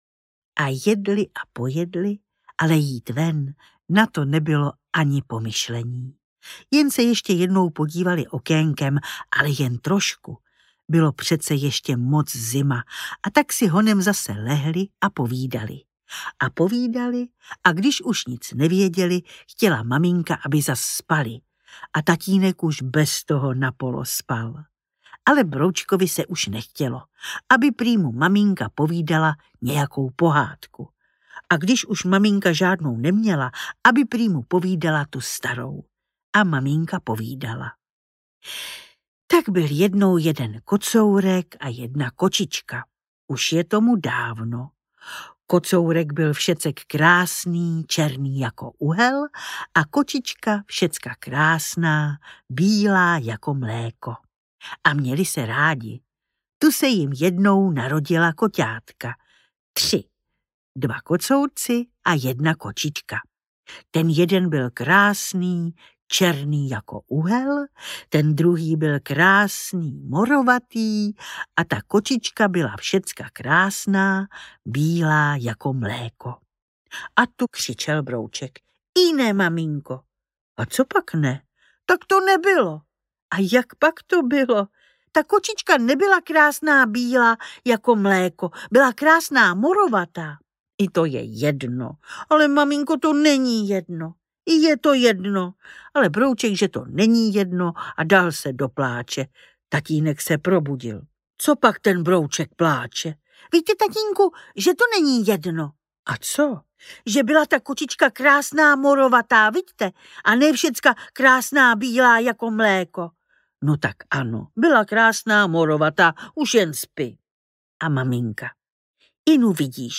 Broučci audiokniha
Ukázka z knihy
• InterpretNaďa Konvalinková